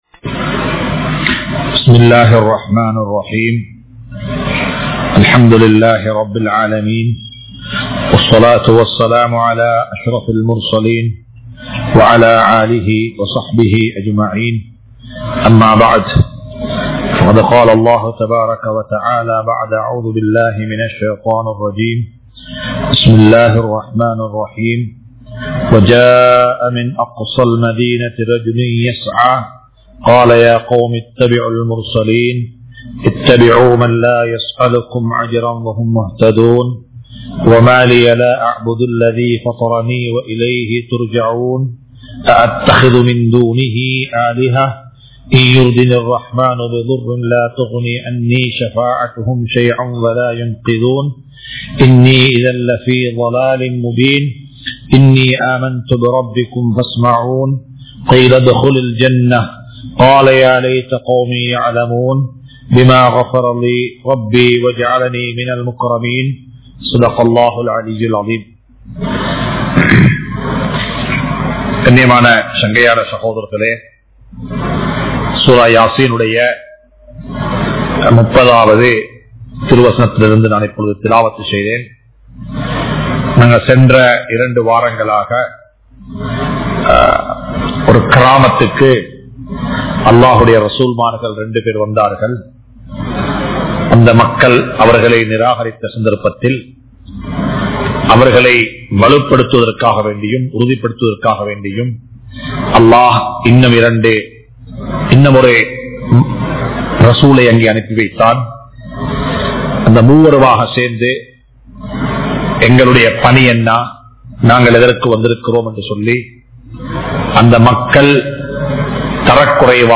Surah Yaseen30 (Thafseer Lesson 184) | Audio Bayans | All Ceylon Muslim Youth Community | Addalaichenai